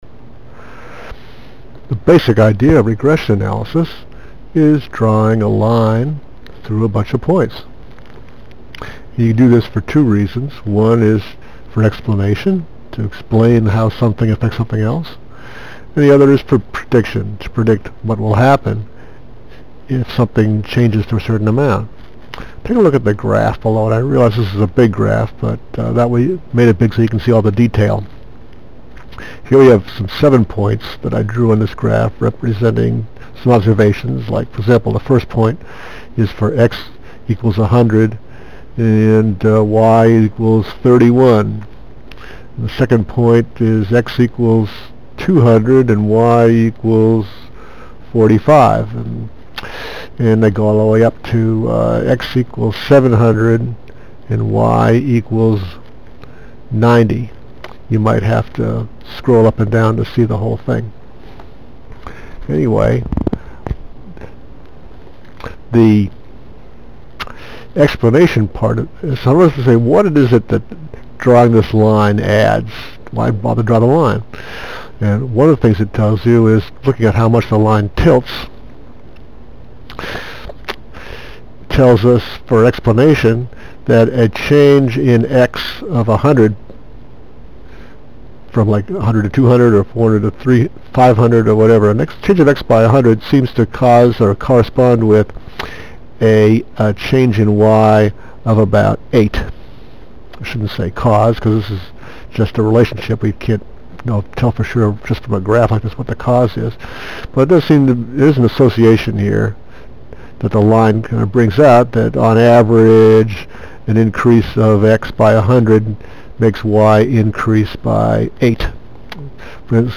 4-minute lecture Click on one of the links below to open the sound recording in a new window or tab. Once the sound starts, switch back to this window. regressionintro.mp3 . regressionintro.au , Regression analysis: drawing lines or curves to summarize data.